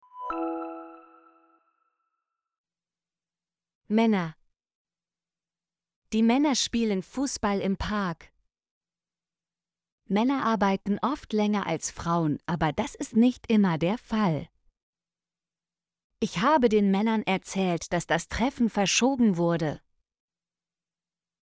männer - Example Sentences & Pronunciation, German Frequency List